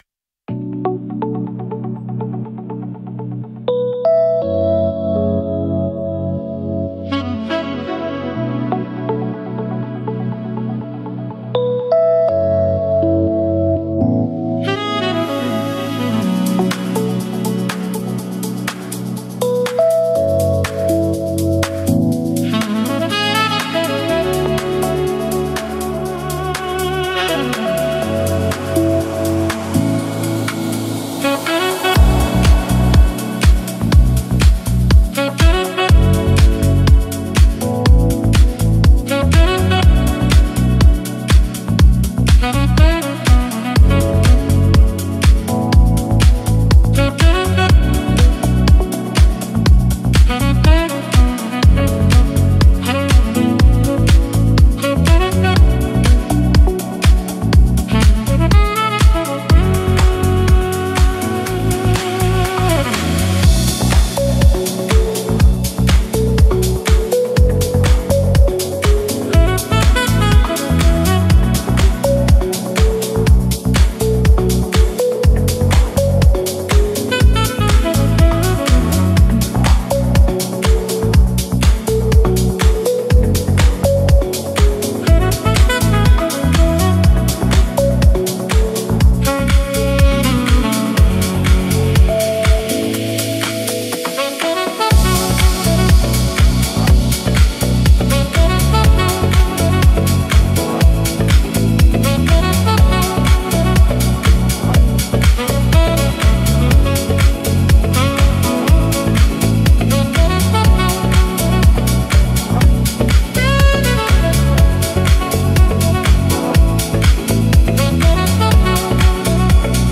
pause_music_04.mp3